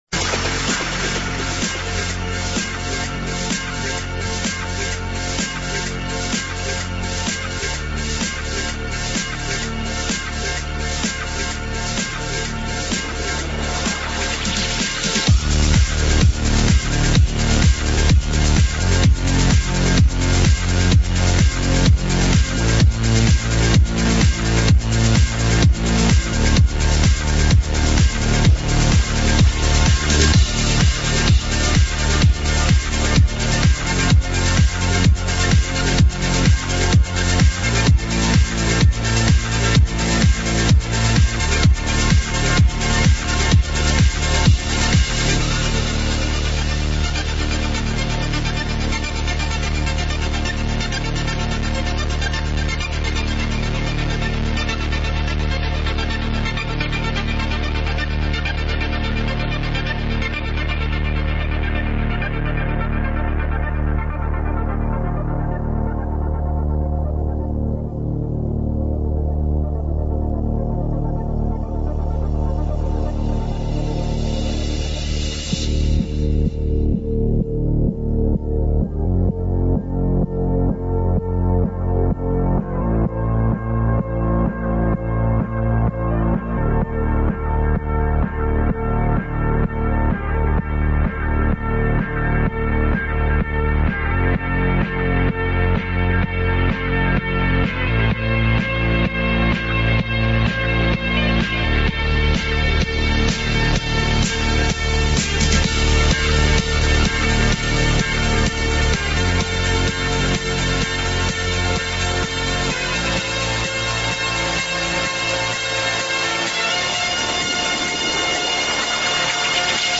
(стиль electro house)